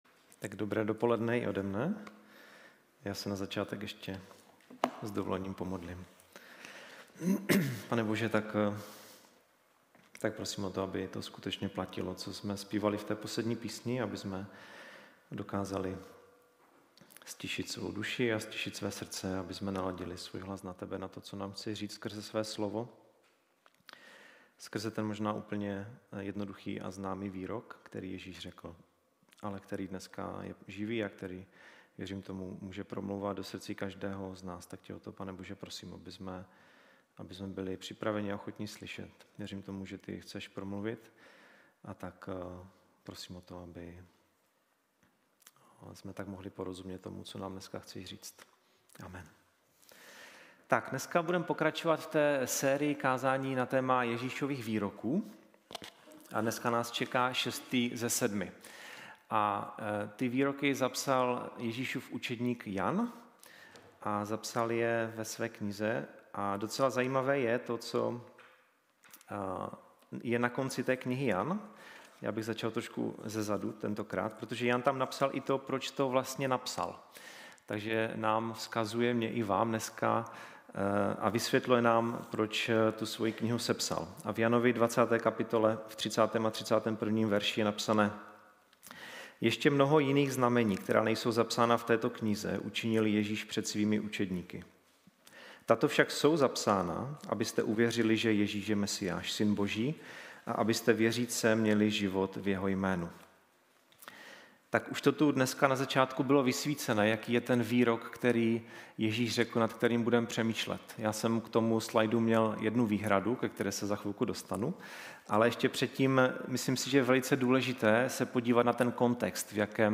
Záznamy kázání z nedělních bohoslužeb a další vyučování z našeho křesťanského společenství.